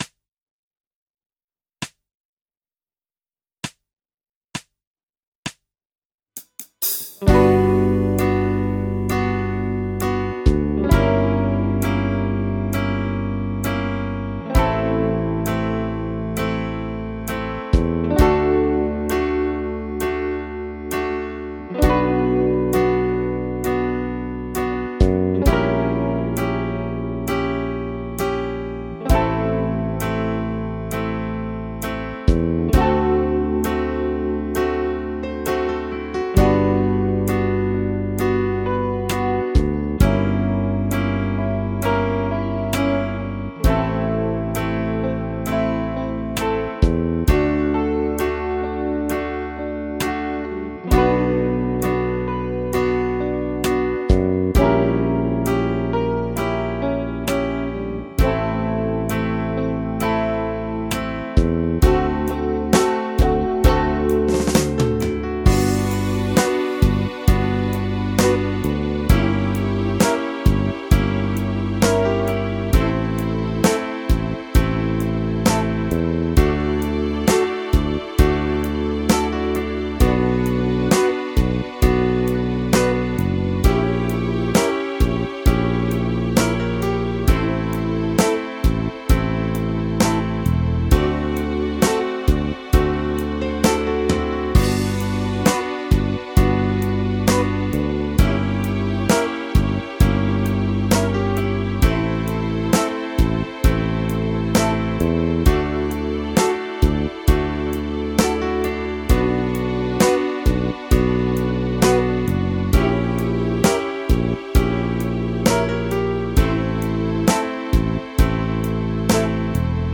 ディミニッシュ・スケール ギタースケールハンドブック -島村楽器